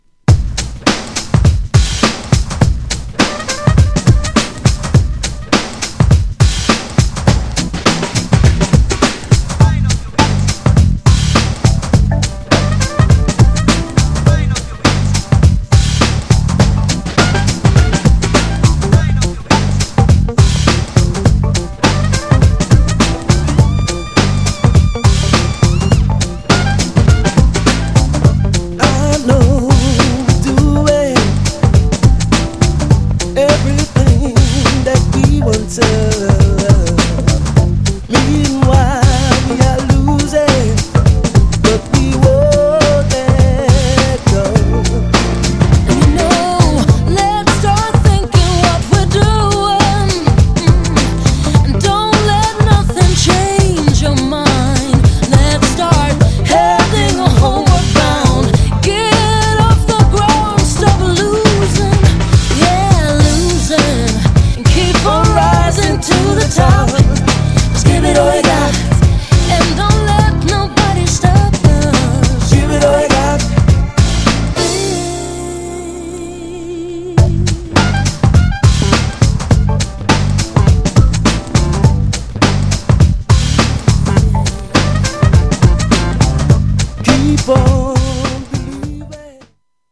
両面人気のUK R&B。
GENRE R&B
BPM 91〜95BPM